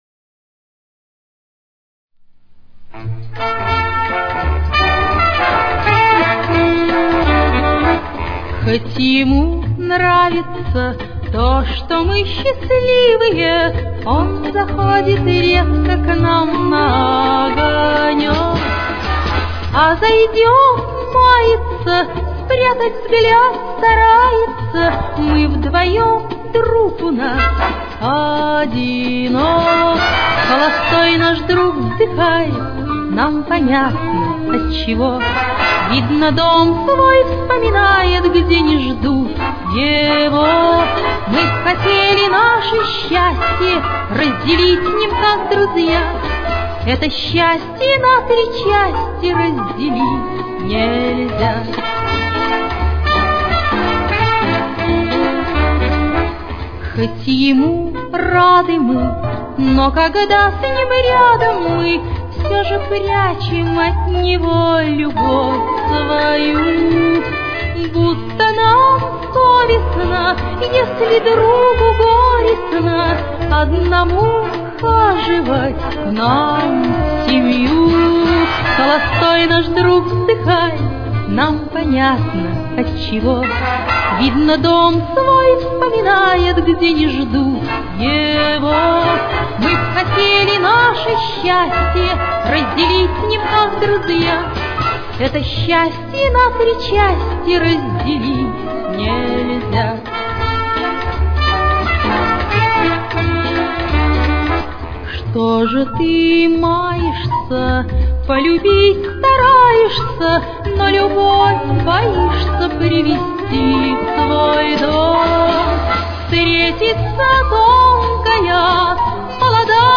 Ля минор. Темп: 90.